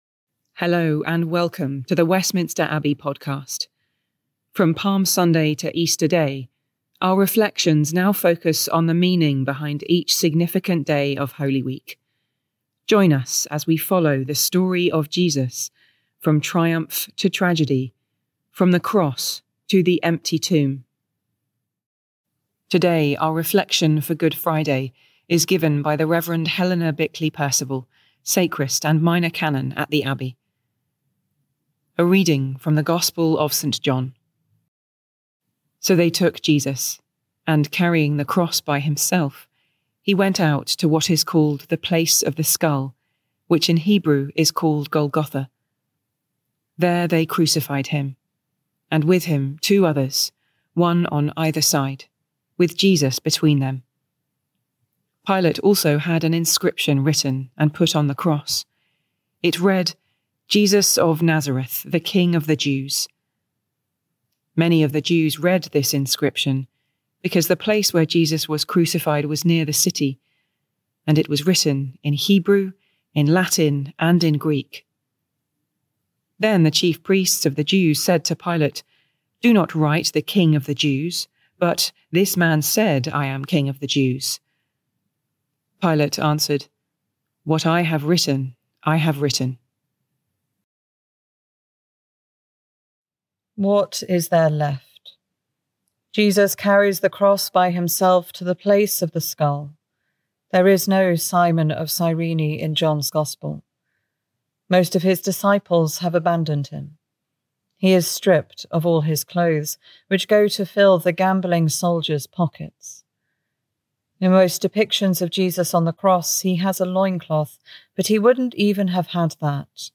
Listen to a reflection for Good Friday